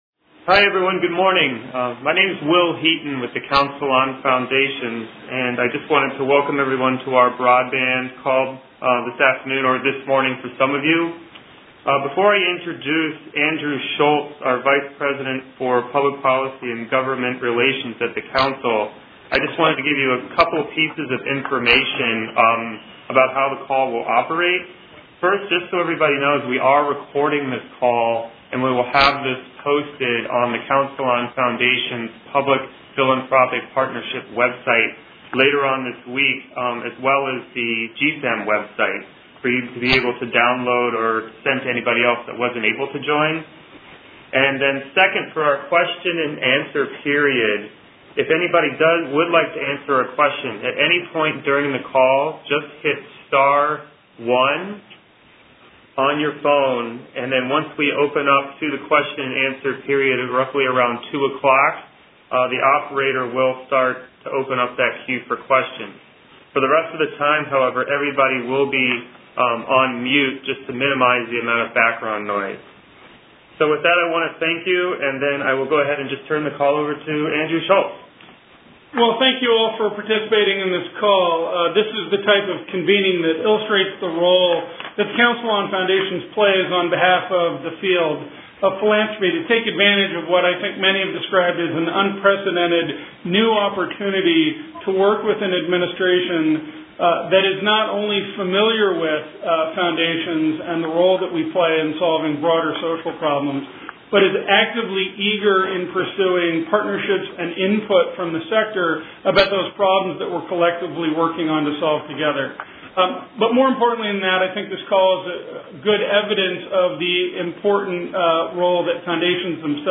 On Tuesday, January 26, 2010, Grantmakers in Film + Electronic Media, the Council on Foundations and the Rural Funders Collaborative held a teleconference briefing call on how funders can engage with the upcoming, final round of Broadband Stimulus funding.